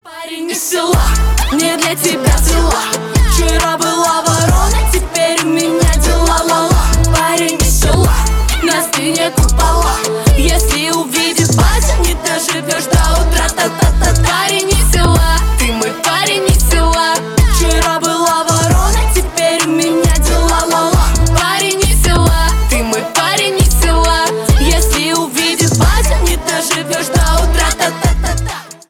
Поп Музыка
весёлые
милые